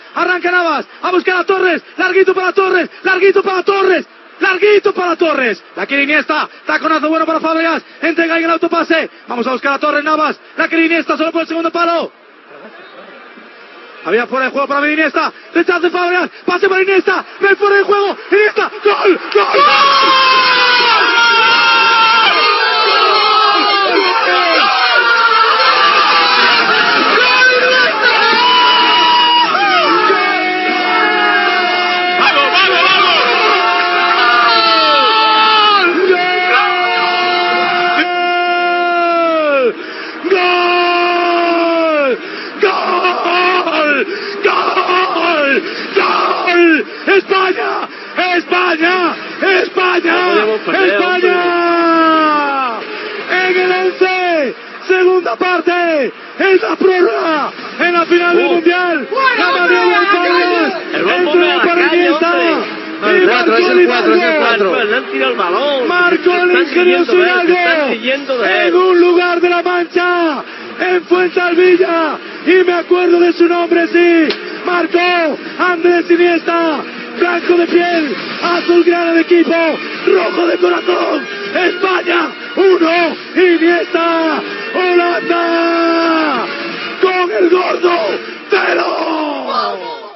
Transmissió del partit de la final de la Copa del Món de Futbol masculí 2010, des de Sud-àfrica.
Esportiu